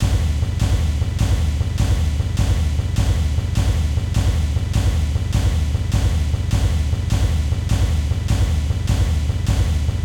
machine-loop-02.ogg